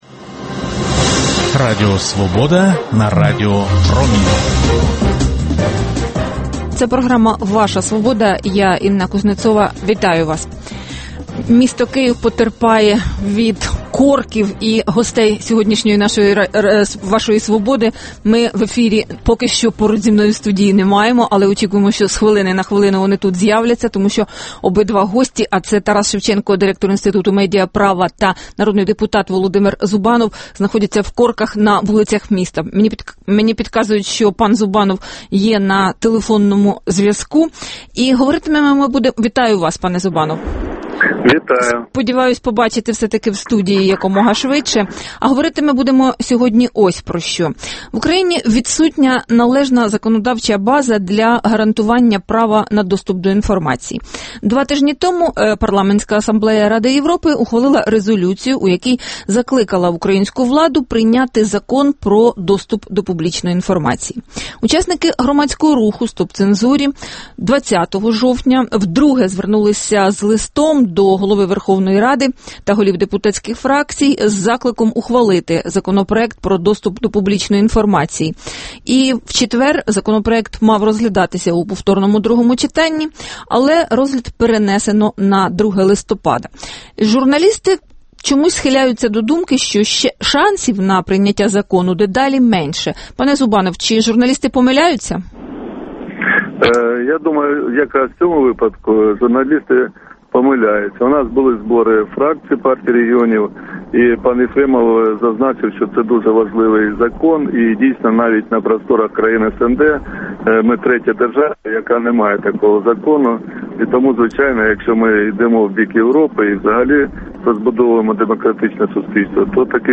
Дискусія про головну подію дня